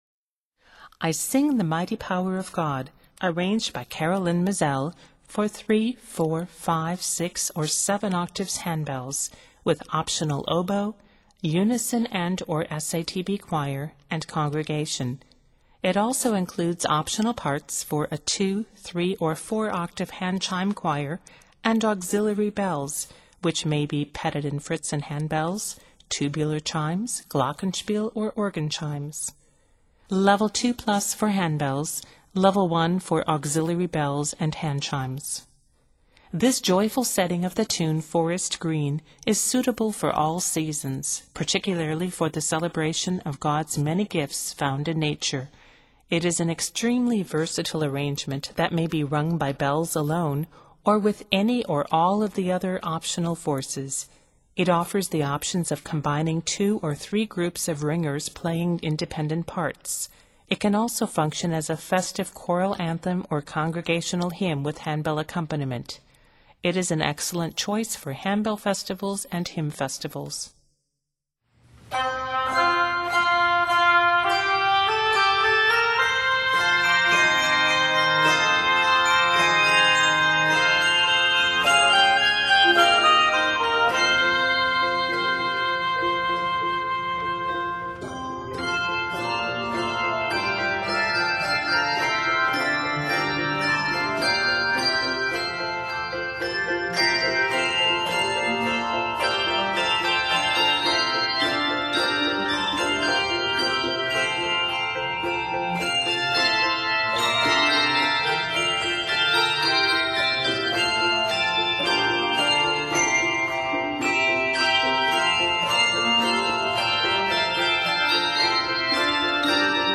This joyful setting
A total of 77 measures, this work is set in F Major.